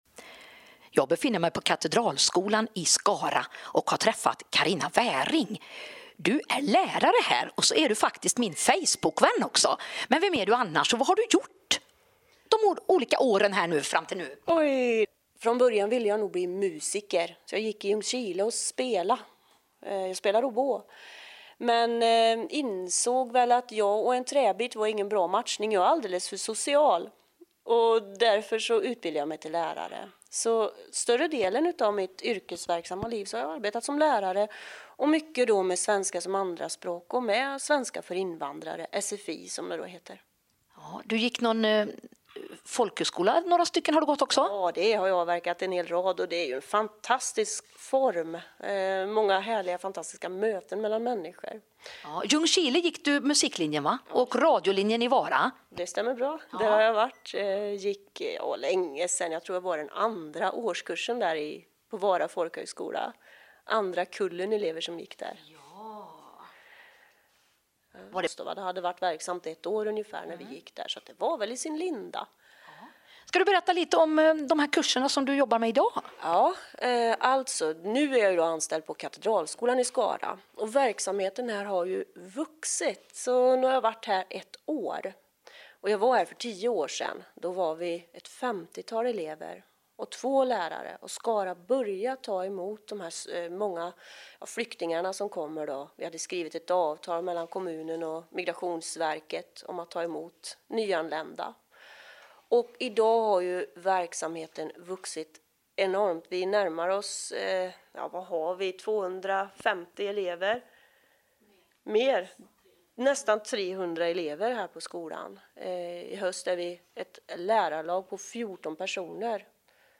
En mycket intressant och givande intervju.